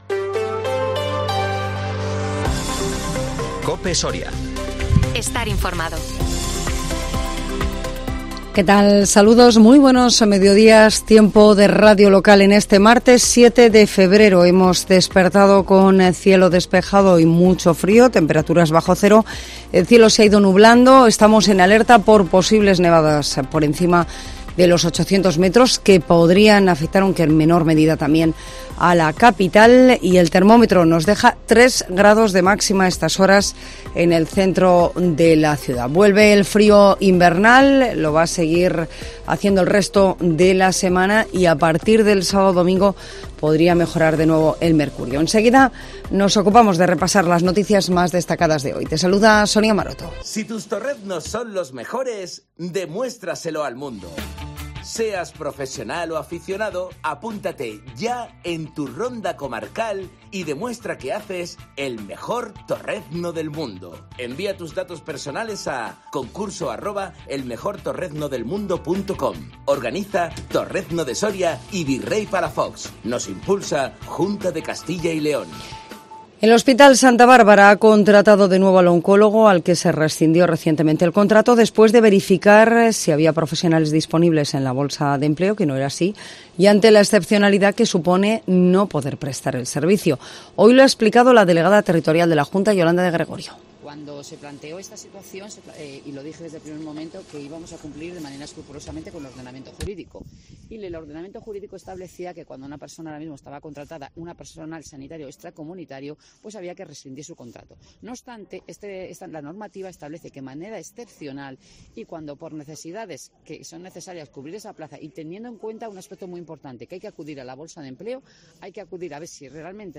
INFORMATIVO MEDIODÍA COPE SORIA MARTES 7 FEBRERO 2023